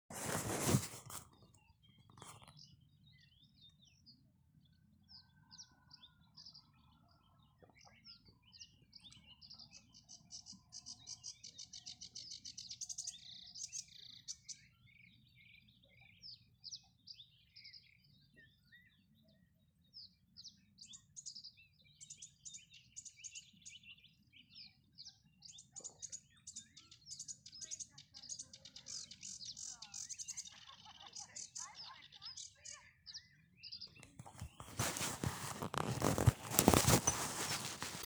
Sedge Warbler, Acrocephalus schoenobaenus
StatusSinging male in breeding season